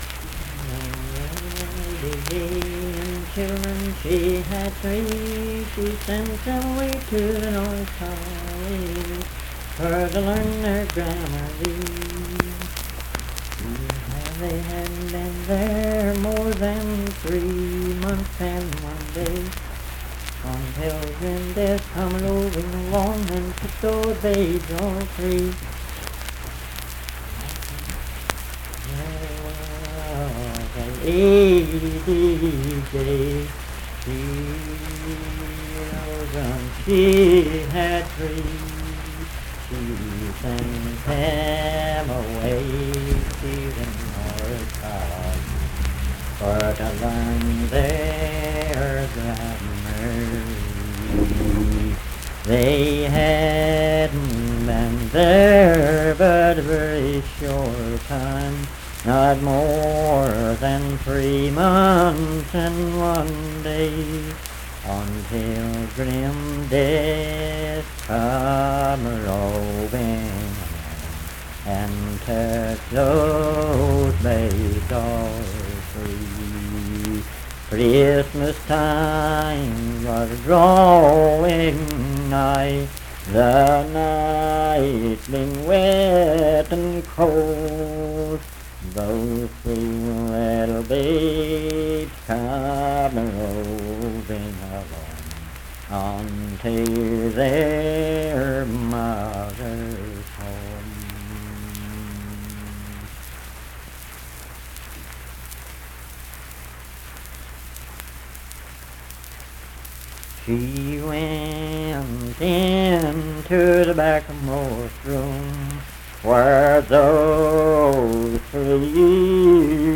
Unaccompanied vocal music
Verse-refrain 5(4).
Performed in Dundon, Clay County, WV.
Voice (sung)